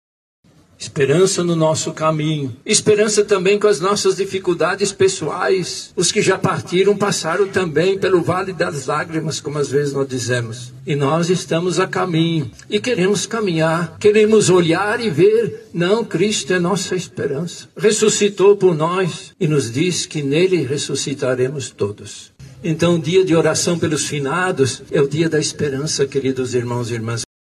O Cardeal Dom Leonardo Steiner, arcebispo metropolitano de Manaus, presidiu a primeira missa deste domingo, 02, do Cemitério São João Batista, localizado na zona centro-sul da capital amazonense. Durante a homilia destacou a esperança.